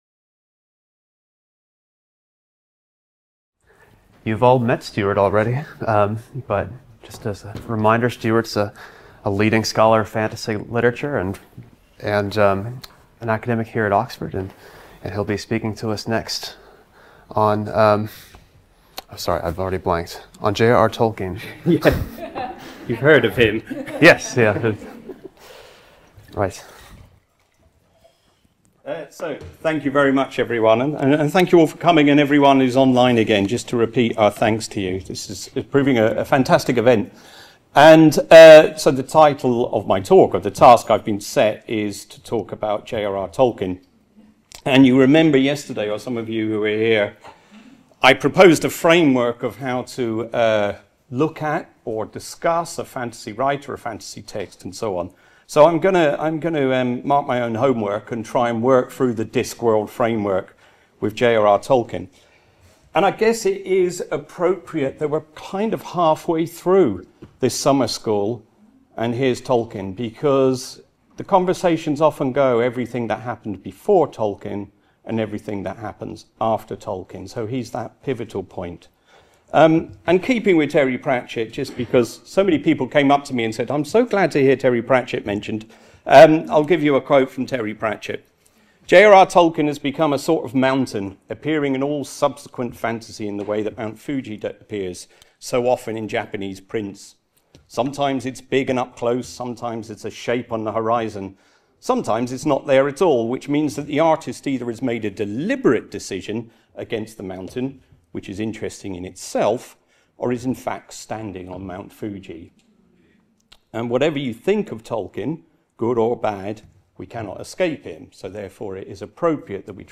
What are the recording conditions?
Part of the Bloomsbury-Oxford Summer School (23rd-25th September 2025) held at Exeter College.